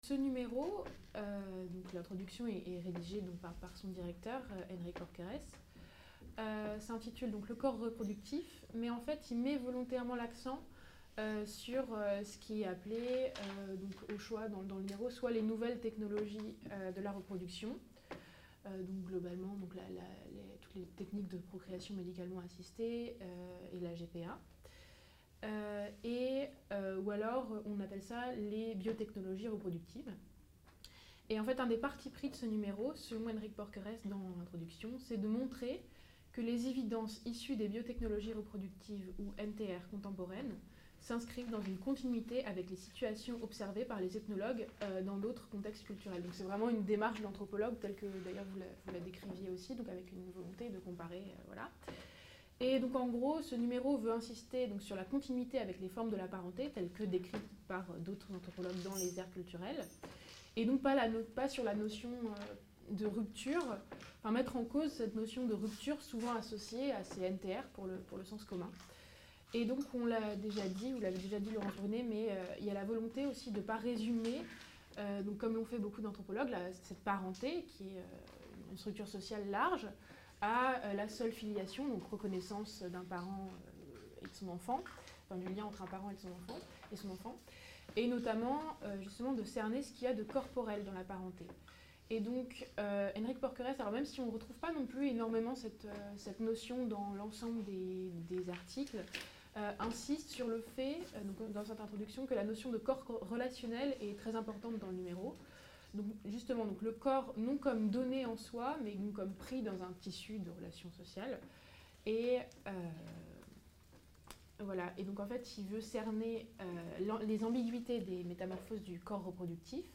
Séminaire "Corps" et sciences sociales- Présentation critique d'ouvrages 2 ème partie | Canal U